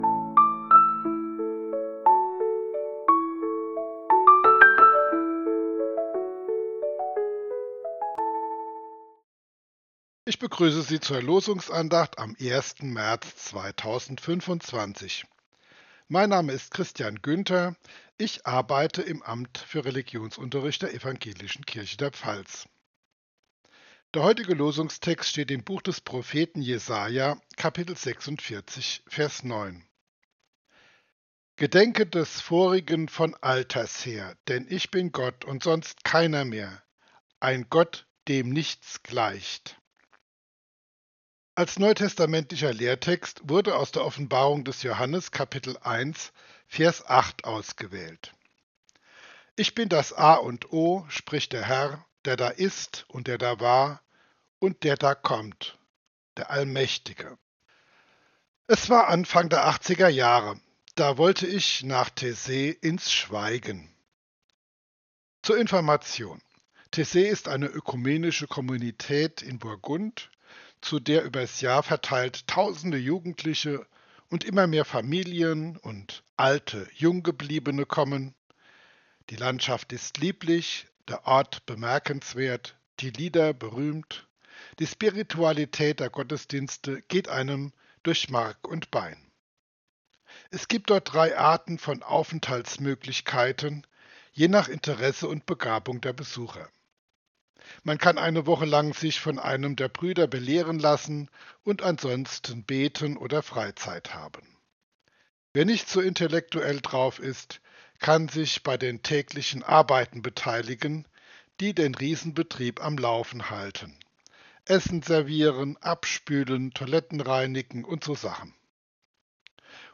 Losungsandacht für Samstag, 01.03.2025